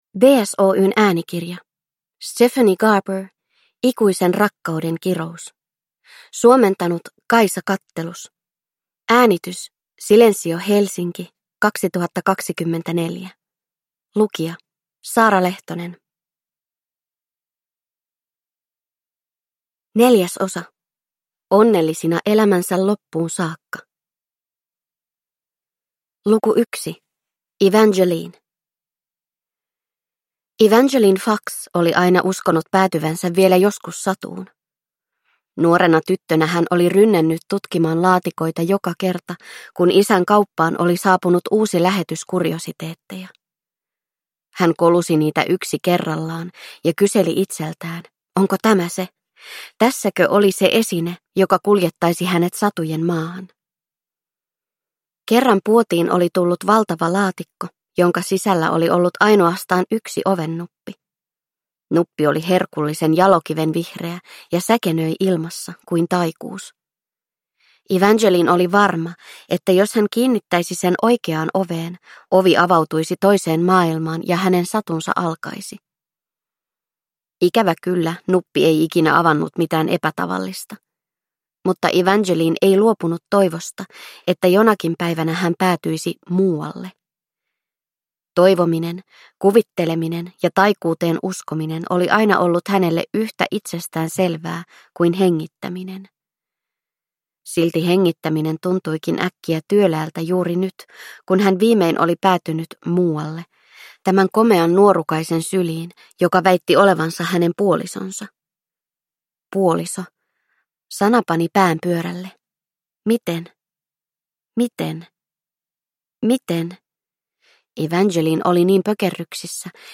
Ikuisen rakkauden kirous – Ljudbok